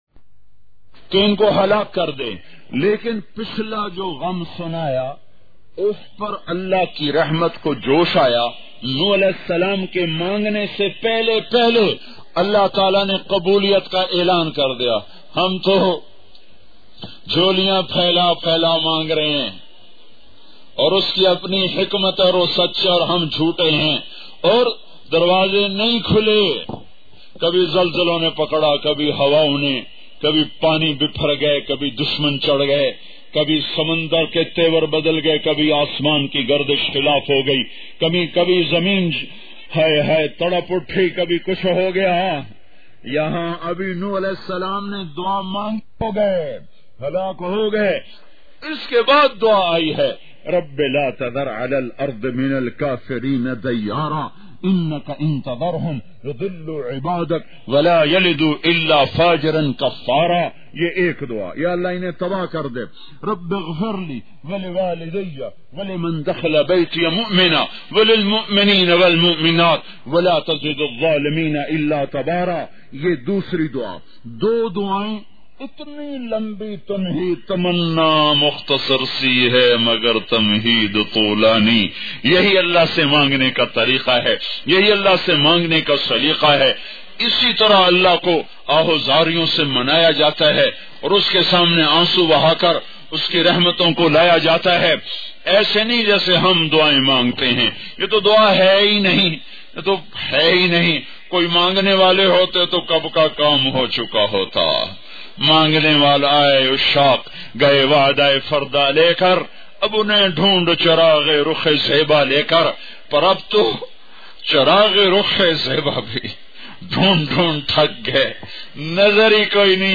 ALLAH KI TALWAR part 2 bayan mp3